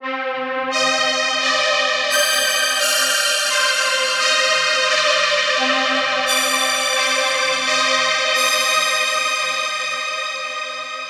Vibes Strings 03.wav